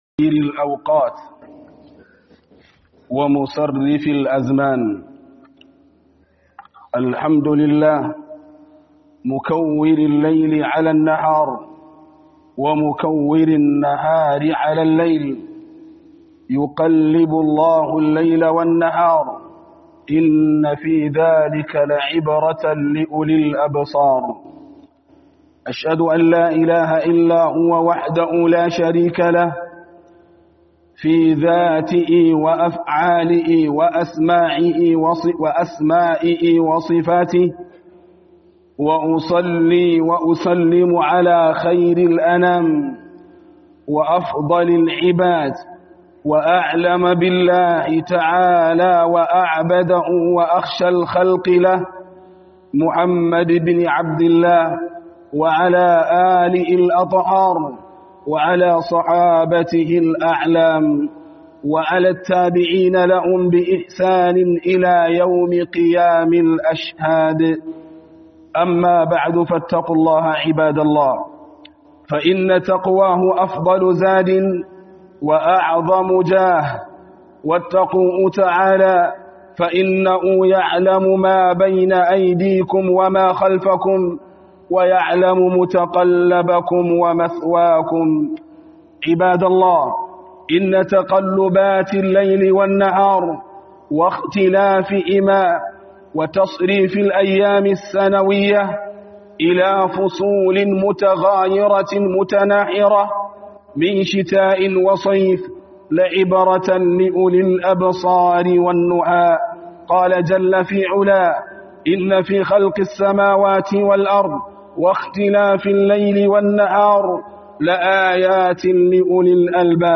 HUDUBA JUMA'A JUJJUYAWAN YANAYI